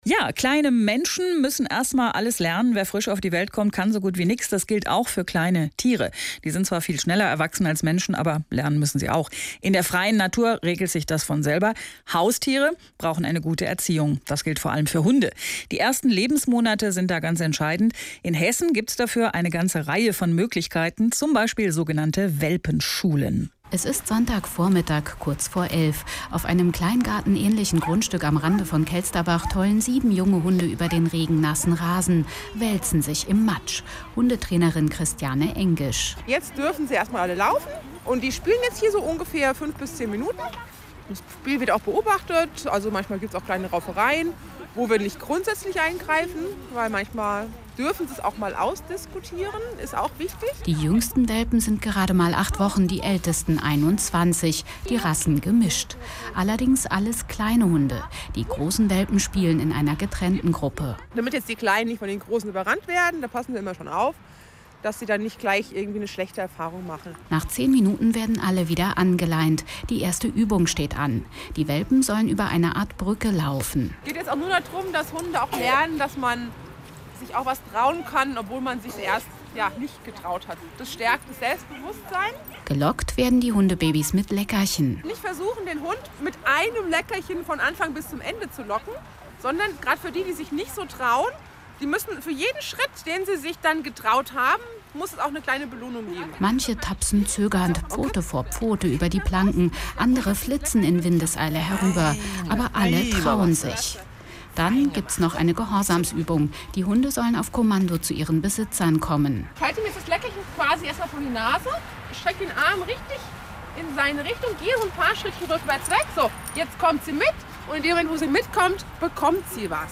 HR1 Radio-Interview Live aus der Welpenspiel – und Lerngruppe